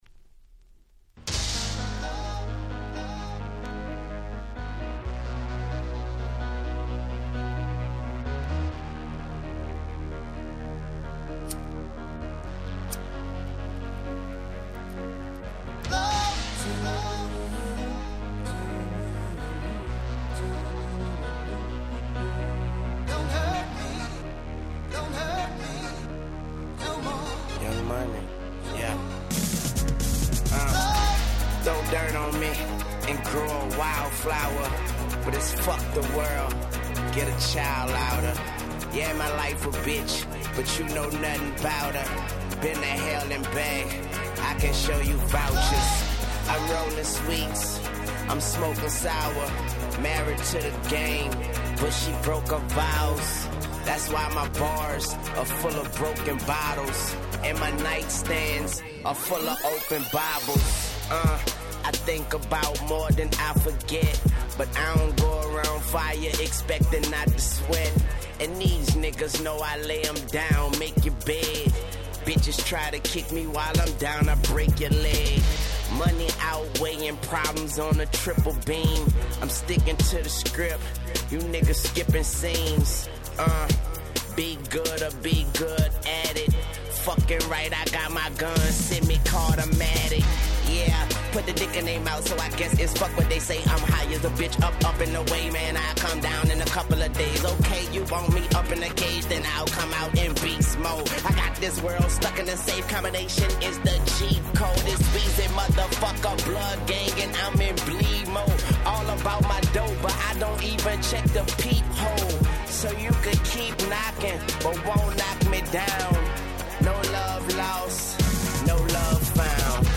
10' Super Hit Hip Hop !!